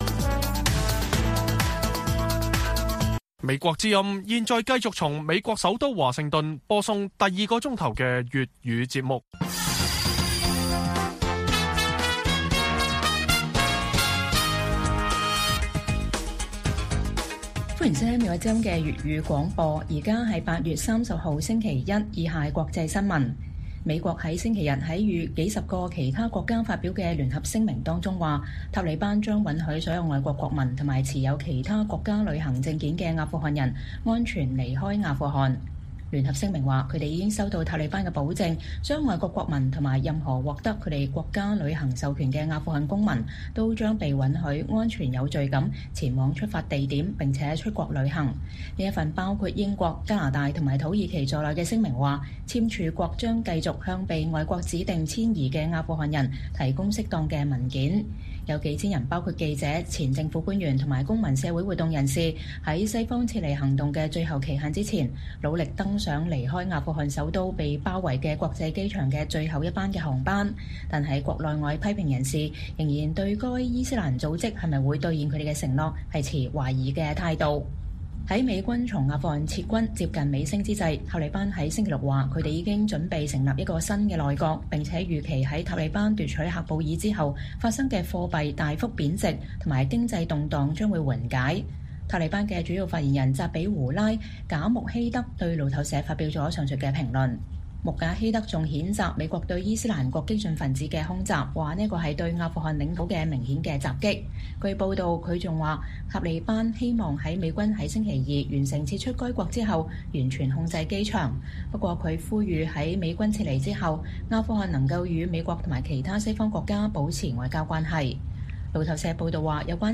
粵語新聞 晚上10-11點: 數十個國家稱塔利班承諾為撤離人員提供安全通道